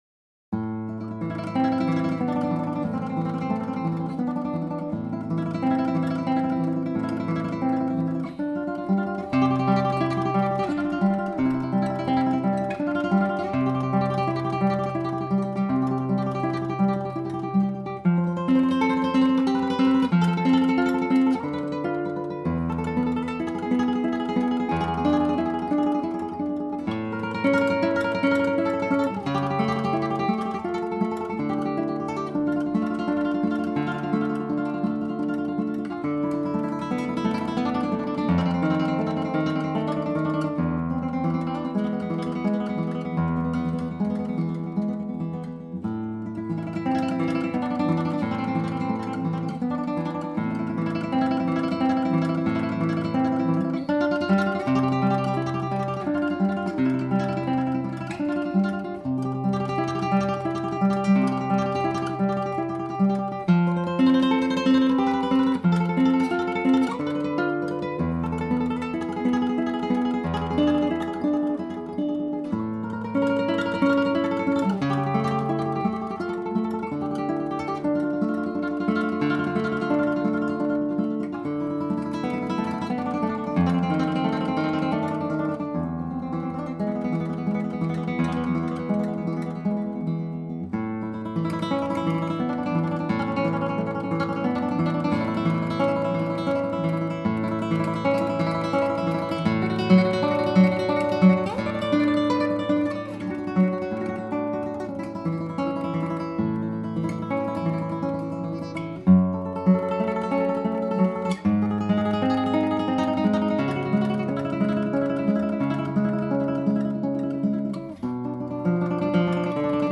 Voicing: Guitar CD